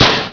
GasTank.wav